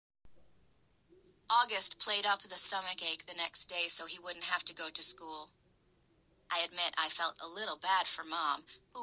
اما از فصل 6 به بعد راوی تغییر کرد :point_down:
که انصافا از نوع تلفظ و لهجشم خوشم اومد.